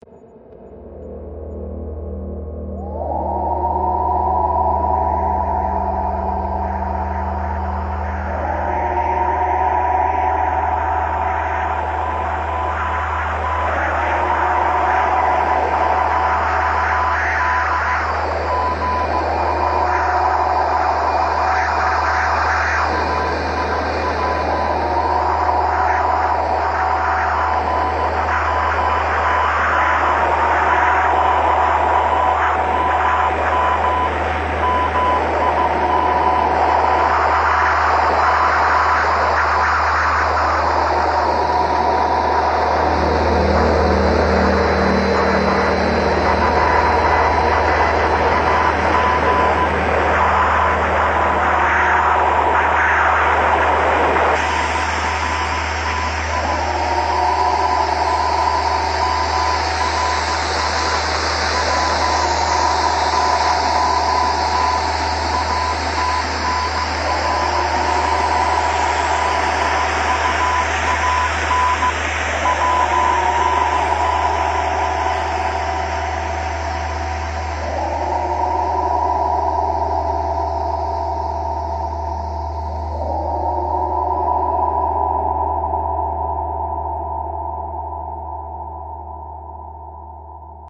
标签： 音景 宇宙 环境 环境 科幻 无人驾驶飞机 FX 空间 SFX 科幻 史诗 大气 忧郁
声道立体声